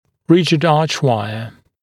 [‘rɪʤɪd ‘ɑːʧˌwaɪə][‘риджид ‘а:чˌуайэ]жесткая дуга